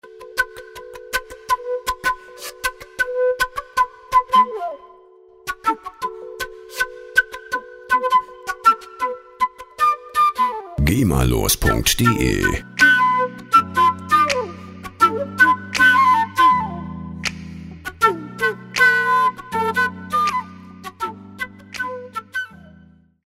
Instrument: Blasinstrument
Tempo: 80 bpm